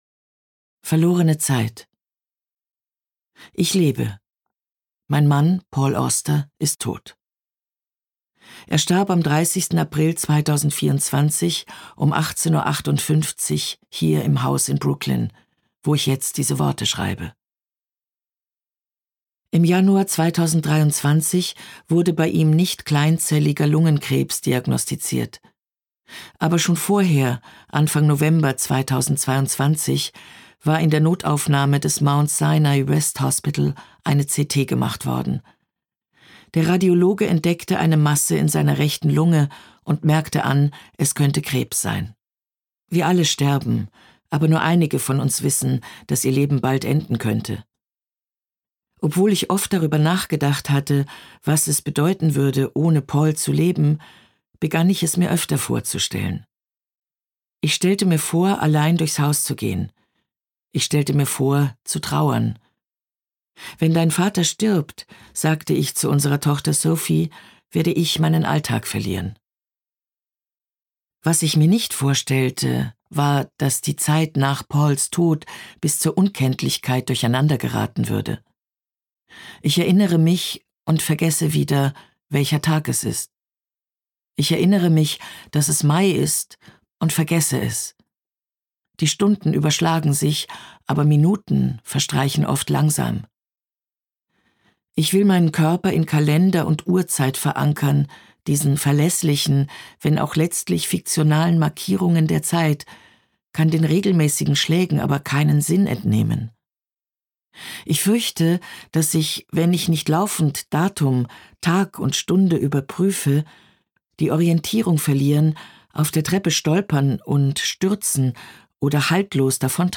Gekürzt Autorisierte, d.h. von Autor:innen und / oder Verlagen freigegebene, bearbeitete Fassung.
Ghost Stories Gelesen von: Eva Mattes, Heikko Deutschmann